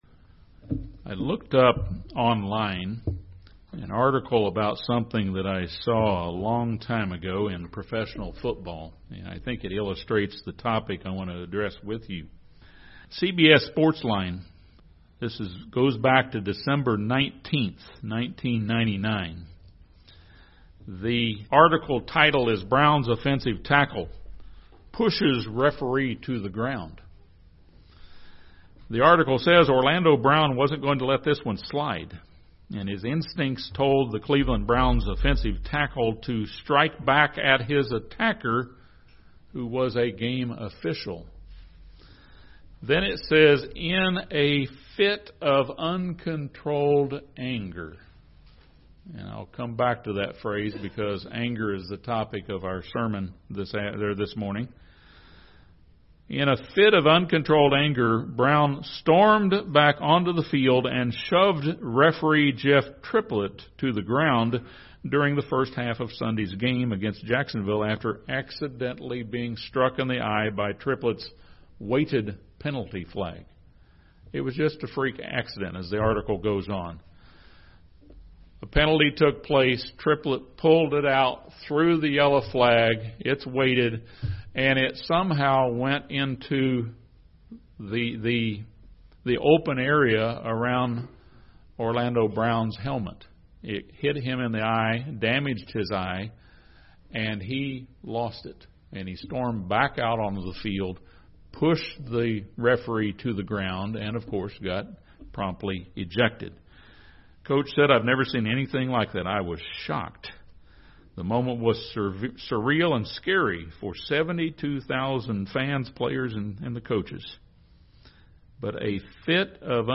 Christians must battle to control the emotion of anger. This sermon discusses three Greek words that are translated anger, indignation or wrath in the New Testament. It also offers five basic points to use in our quest for self-control.